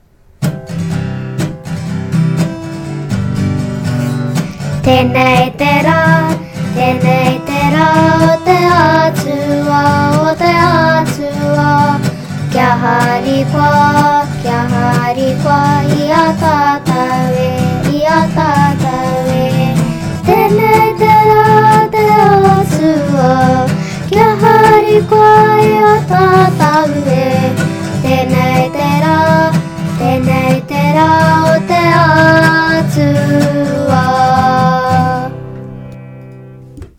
Playlist of Te Aka Studio recorded waiata:
Tenei-Te-Ra-sung-by-nga-rangatahi-o-Te-Manawa-o-Te-Wheke.mp3